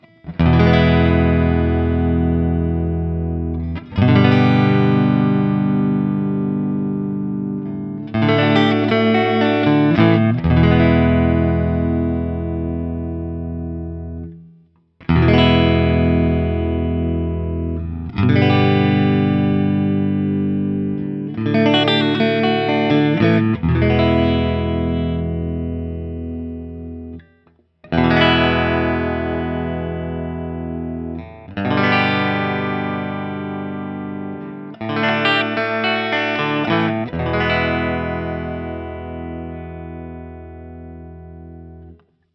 ODS100 Clean
Open Chords #1
As usual, for these recordings I used my normal Axe-FX II XL+ setup through the QSC K12 speaker recorded direct into my Macbook Pro using Audacity.
For each recording I cycle through the neck pickup, both pickups, and finally the bridge pickup.
Finally, I have to point out that this guitar is an absolute sustain monster and a strummed open chord just rings and rings.